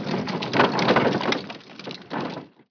wagon6.wav